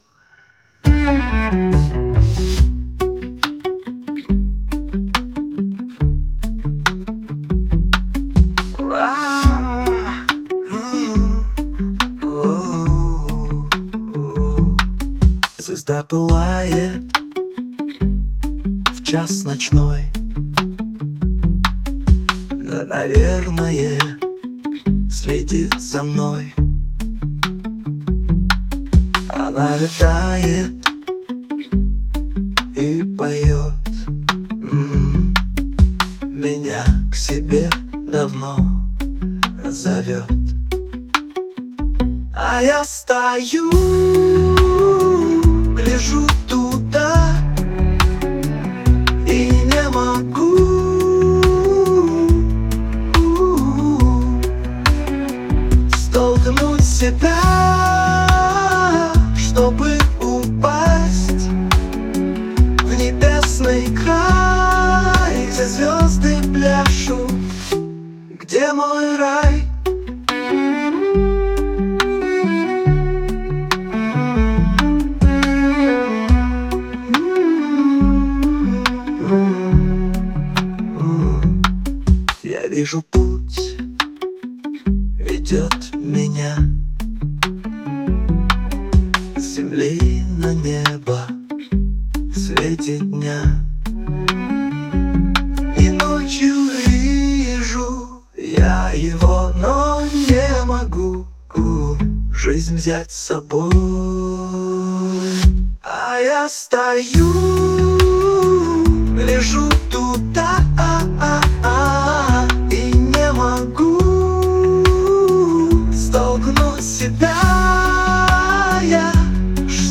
reverb vocal, acoustic guitar, cello, drums